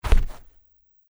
在积雪里行走的脚步声－YS070525.mp3
通用动作/01人物/01移动状态/02雪地/在积雪里行走的脚步声－YS070525.mp3
• 声道 立體聲 (2ch)